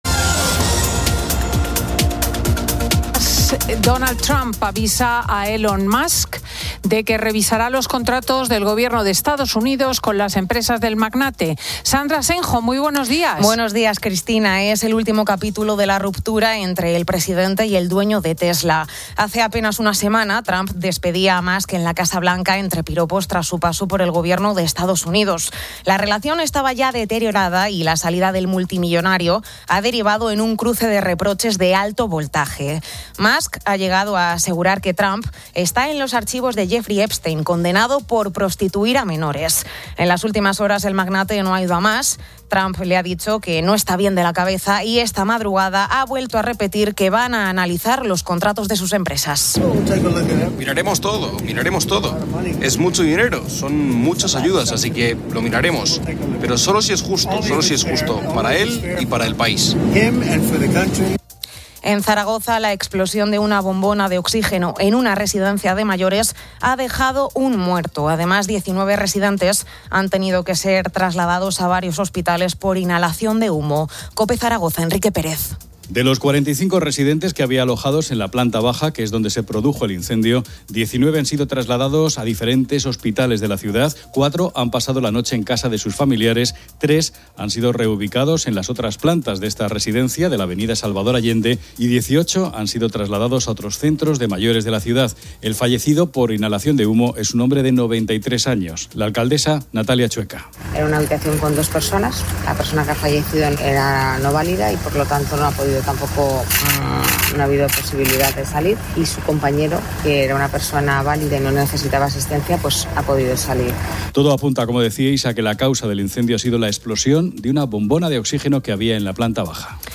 Fin de Semana 10:00H | 07 JUN 2025 | Fin de Semana Editorial de Cristina López Schlichting. Isabel Díaz Ayuso, presidenta de la CAM, conversa en Fin de Semana con Cristina.